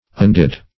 undid - definition of undid - synonyms, pronunciation, spelling from Free Dictionary Search Result for " undid" : The Collaborative International Dictionary of English v.0.48: Undid \Un*did"\, imp. of Undo .
undid.mp3